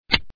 Roblox Scroll Sound Effect Free Download